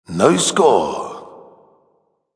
busted Meme Sound Effect
Category: Sports Soundboard
busted.mp3